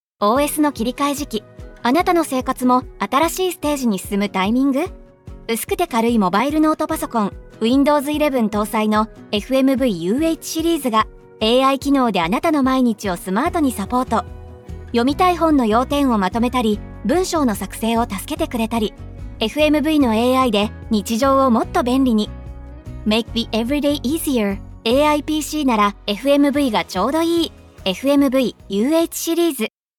同じく、富士通クライアントコンピューティングのノートパソコン「FMV UHシリーズ」のAI機能を訴求する音声CMを制作。